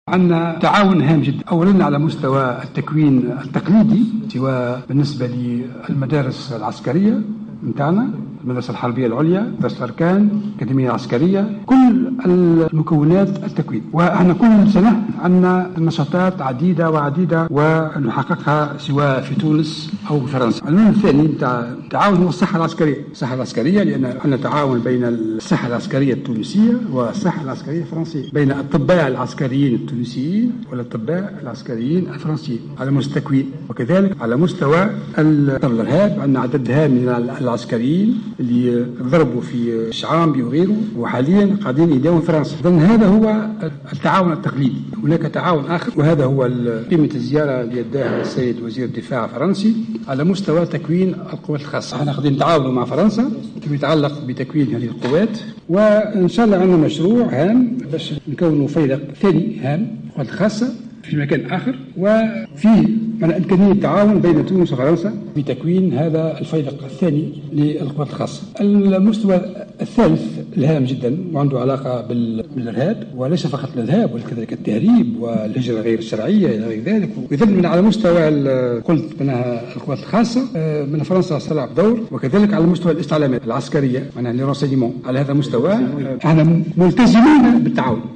تحدّث وزير الدفاع الوطني، فرحات الحرشاني اليوم على هامش لقائه بنظيره الفرنسي عن مجالات التعاون العسكري بين تونس وفرنسا لمجابهة الخطر الإرهابي.